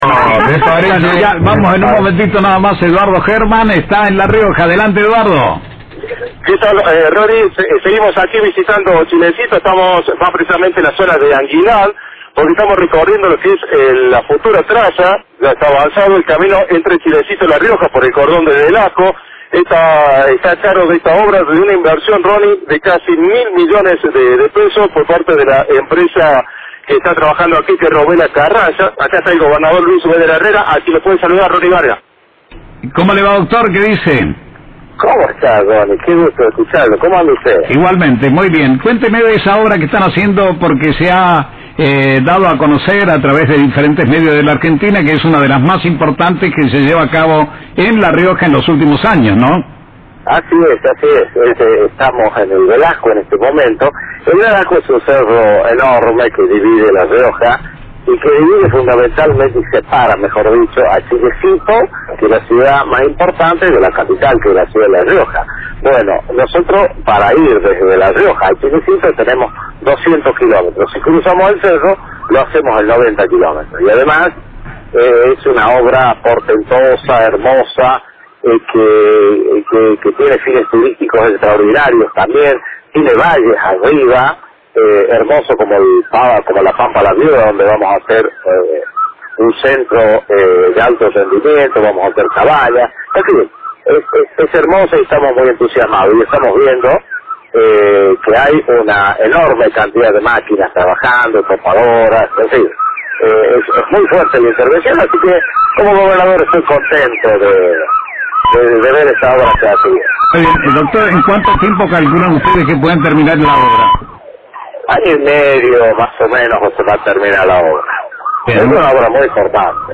Beder Herrera habló por radio Cadena 3 Argentina sobre un tema conflictivo para el gobierno focalizado en Famatina: “No hemos logrado el consenso, no se hizo la exploración y por lo tanto, no vamos avanzar.